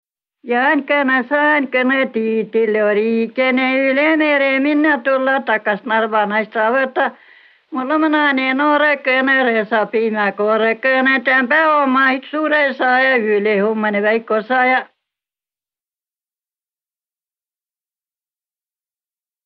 Lugõmisõ’ “Jaan´kõnõ, saan´kõnõ”
Saa pruuki ütehkuuh “Seto aabidsaga” (2011) I-tähe opmisõl vai eräle. Peri plaadi päält “Juttõ ja laulõ seto aabitsa mano”.